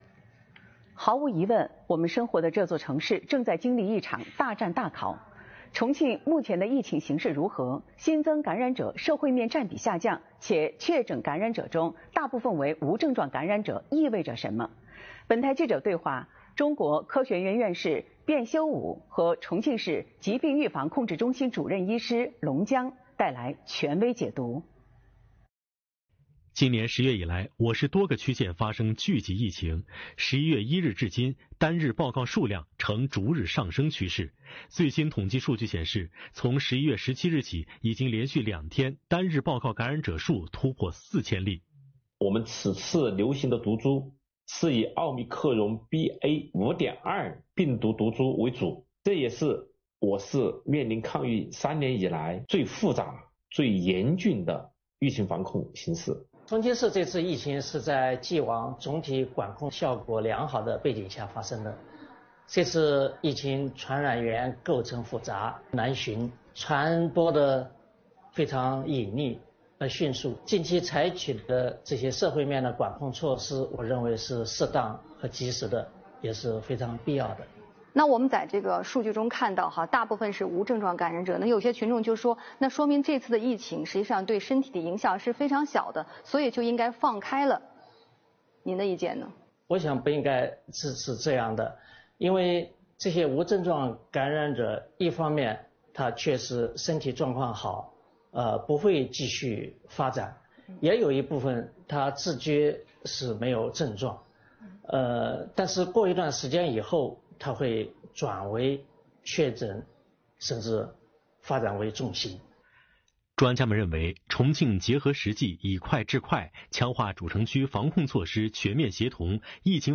日前，重庆日报记者通过视频连线的方式，就本轮疫情防控的相关问题，采访了卞修武院士。